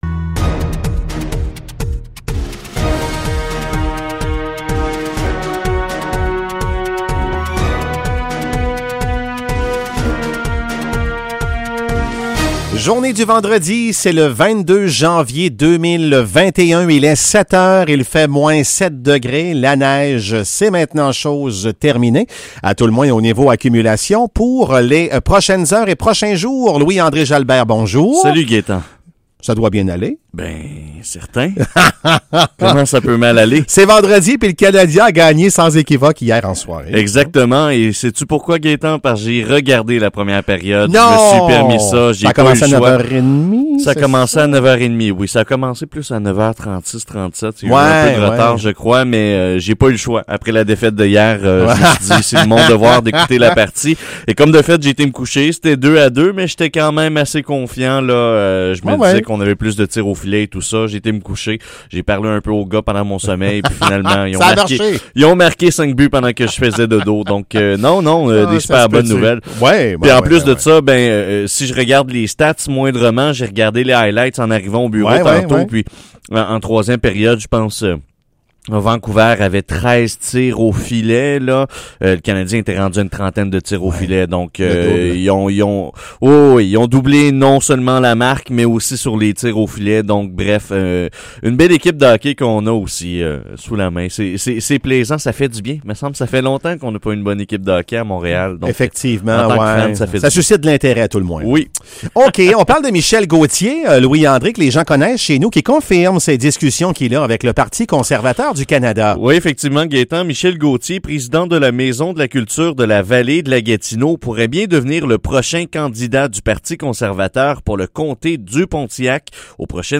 Nouvelles locales - 22 janvier 2021 - 7 h